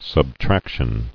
[sub·trac·tion]